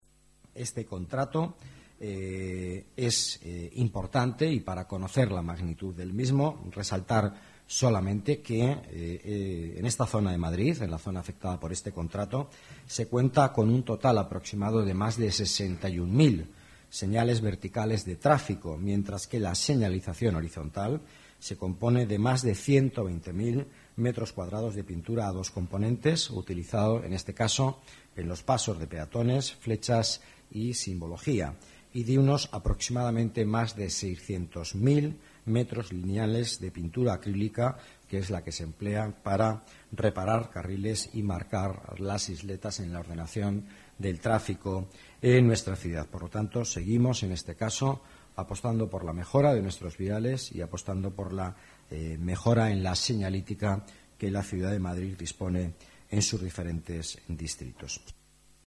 Nueva ventana:Declaraciones vicealcalde, Miguel Ángel Villanueva: mejoras señalización